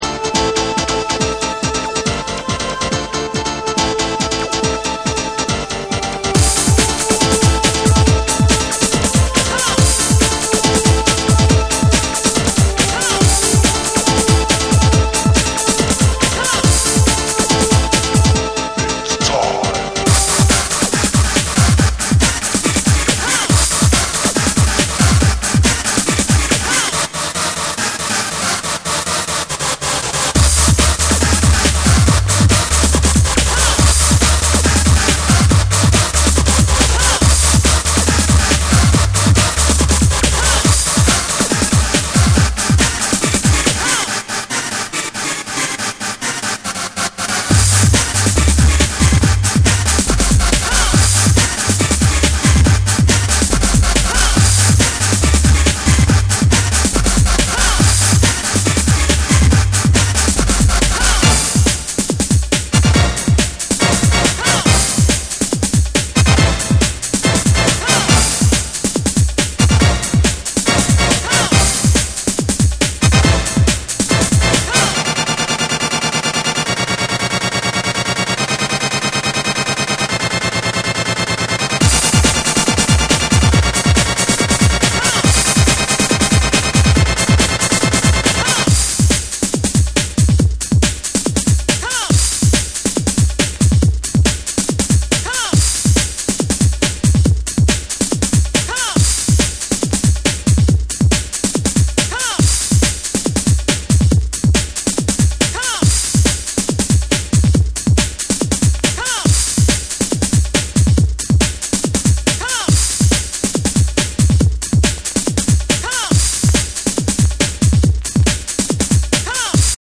ヘヴィーウェイトでリズミカルなリズムに、レイビーなシンセやヴォイス・サンプルが絡み展開するブレイクビーツを収録！
BREAKBEATS